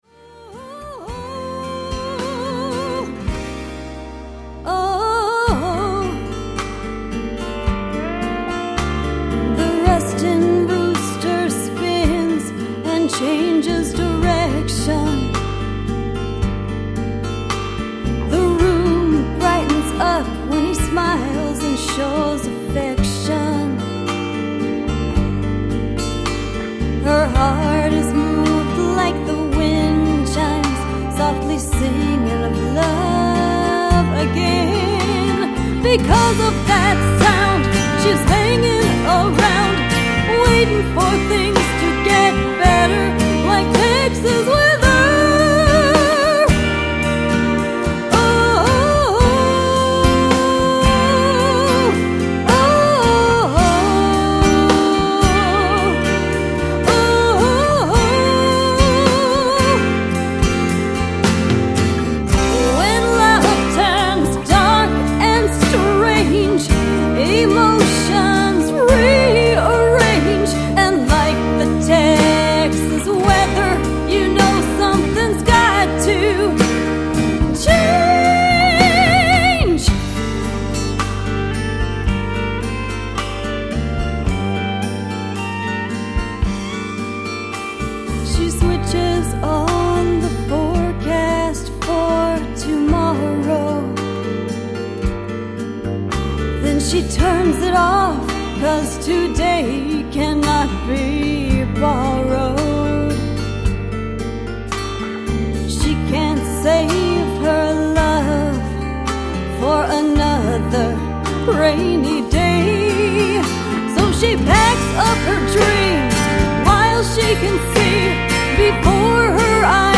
jazzy rock/blues album